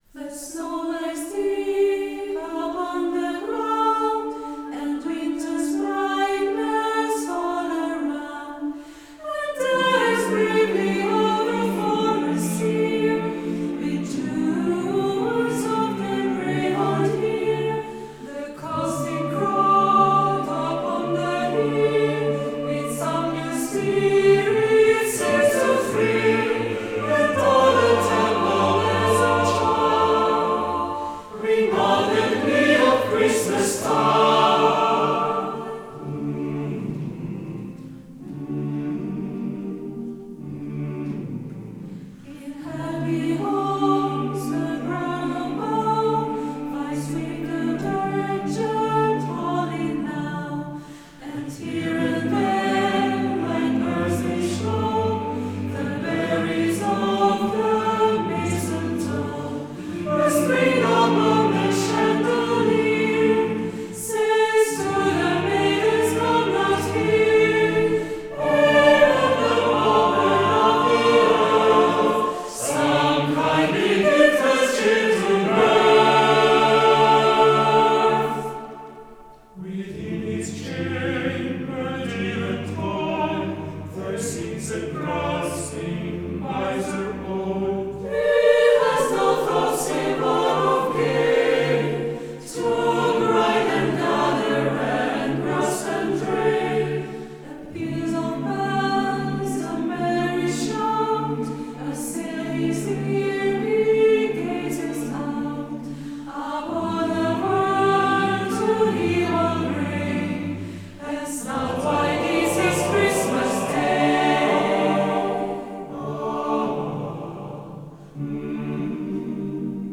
Instrumente - Mixed Choir Tempo - Medium BPM - 82